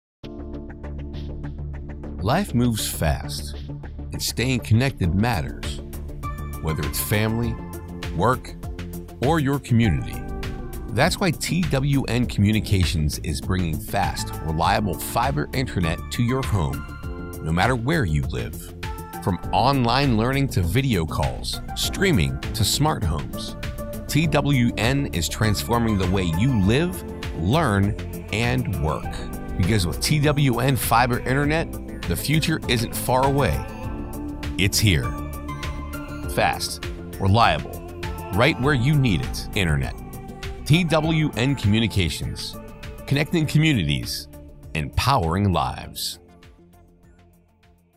Internet Commercial with Background Music